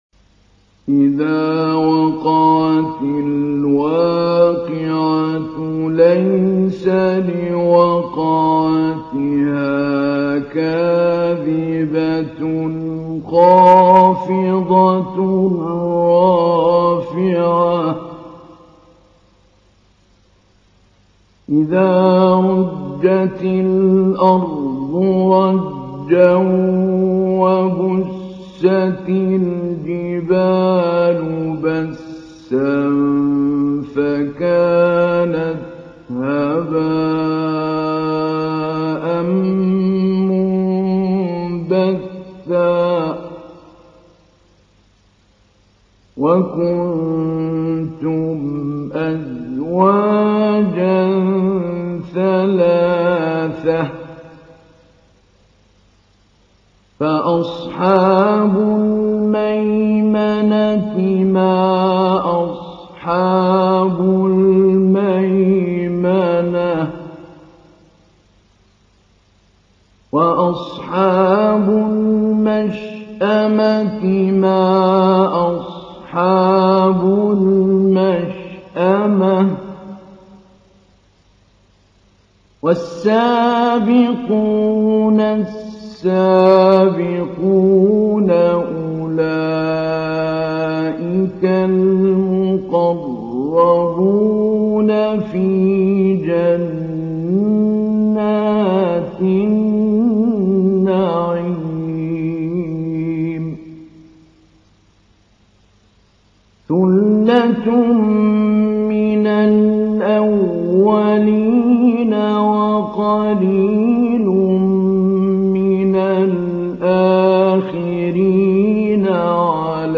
تحميل : 56. سورة الواقعة / القارئ محمود علي البنا / القرآن الكريم / موقع يا حسين